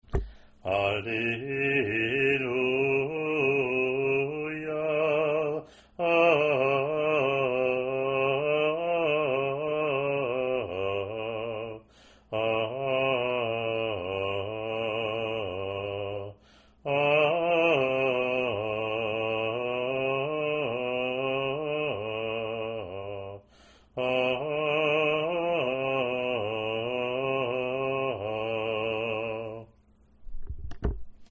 Alleluia (cantor intones first Alleluia, schola repeats and finish the acclamation. Cantor sings verse. Schola repeats acclamation)
ot-pl-alleluia-gm.mp3